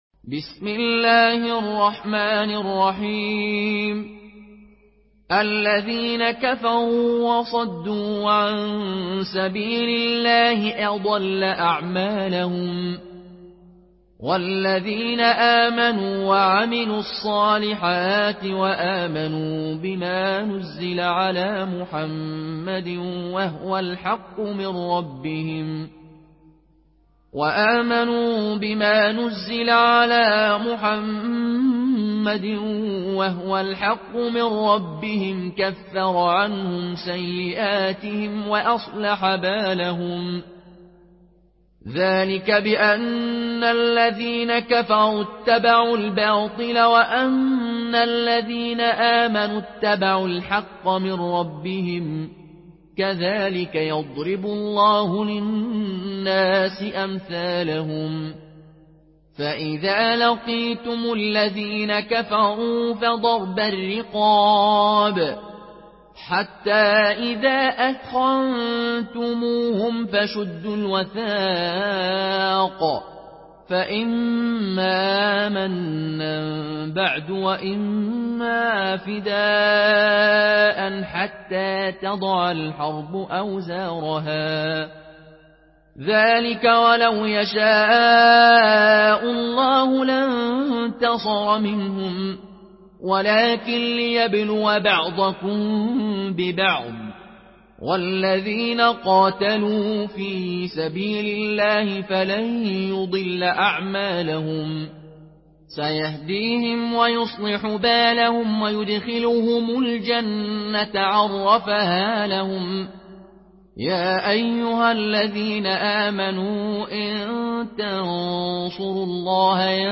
Murattal Qaloon An Nafi